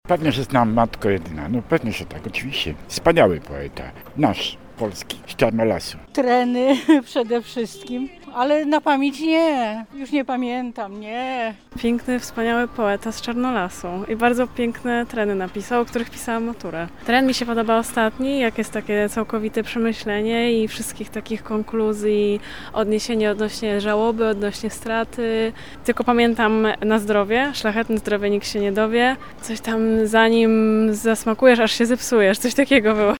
Czy mieszkańcy Gdańska znają Jana Kochanowskiego oraz jego dzieła? O to pytał nasz reporter.